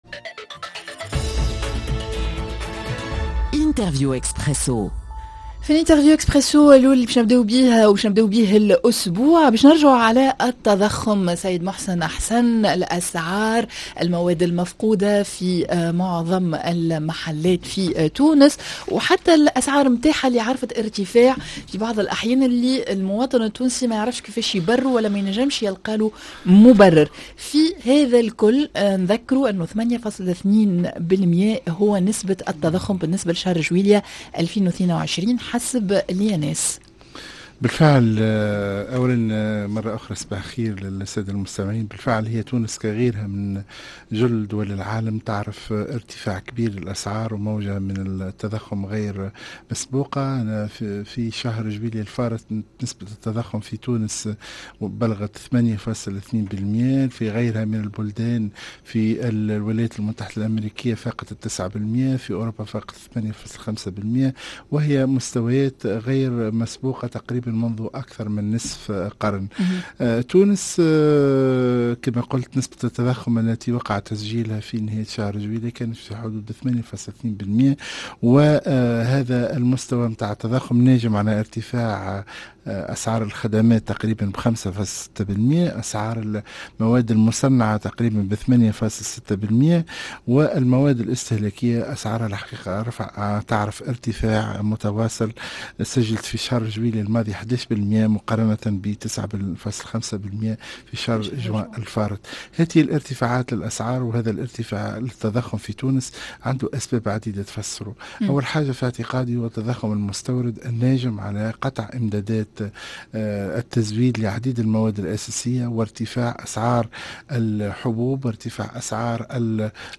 ارتفاع أسعار المواد الغذائية وندرة بعضها في الأسواق،. منظومة الدعم وشنوة أهم الإصلاحات المطلوبة ضيفنا وزير التجارة الأسبق محسن حسن